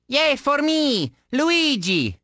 One of Luigi's voice clips from the Awards Ceremony in Mario Kart: Double Dash!!